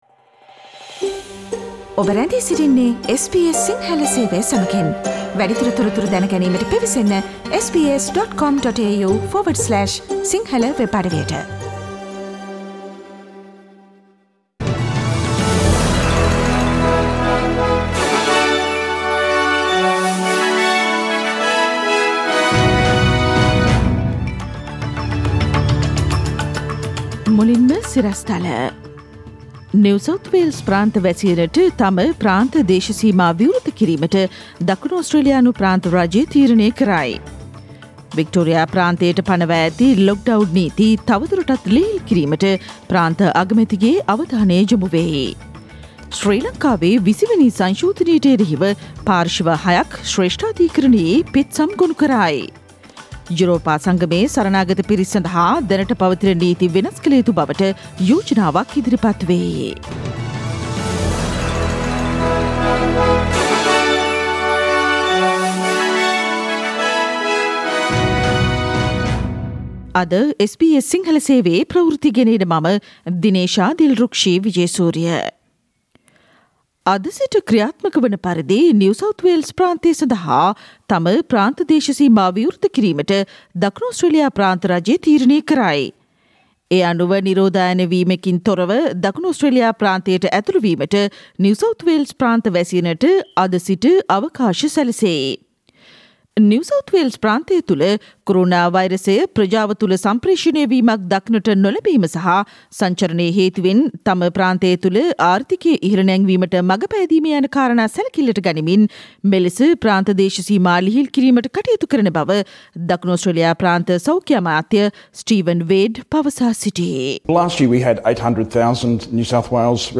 Today’s news bulletin of SBS Sinhala radio – Thursday 24 September 2020.